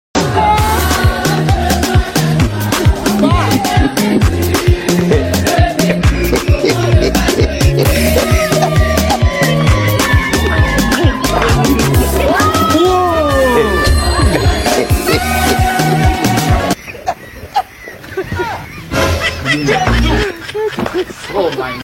Hahahahhaaa sound effects free download